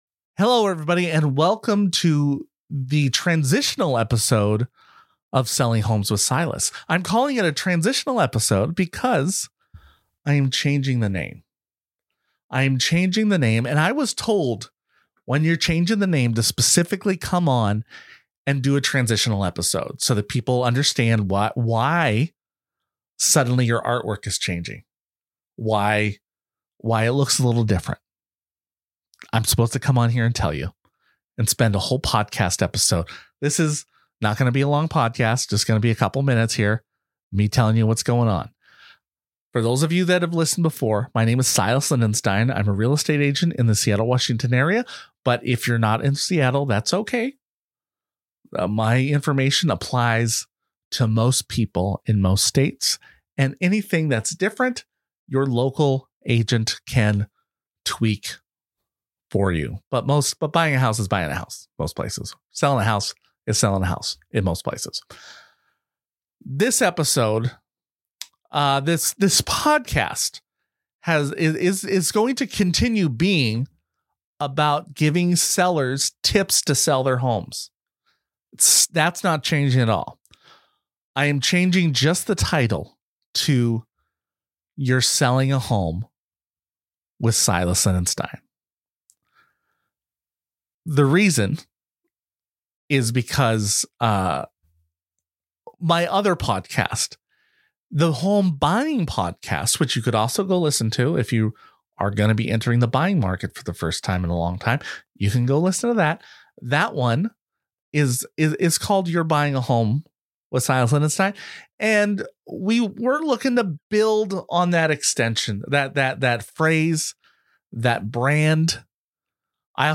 And some new music.